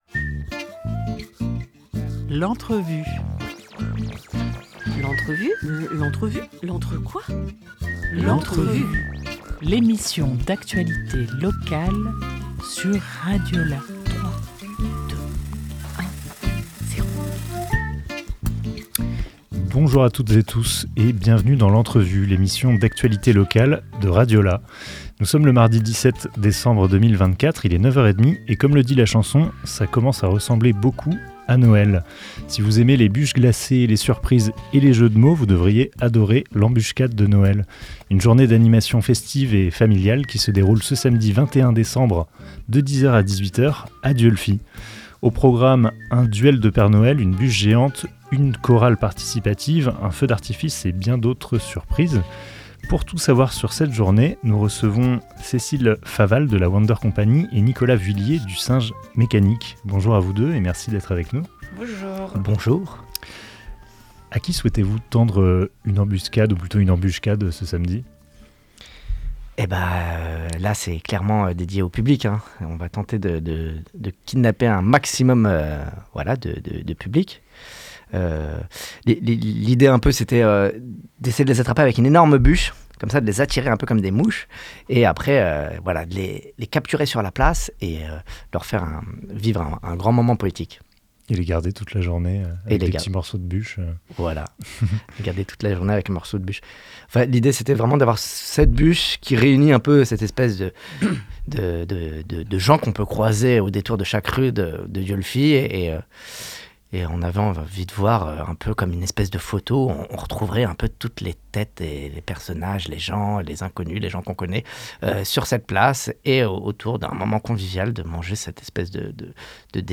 17 décembre 2024 15:15 | Interview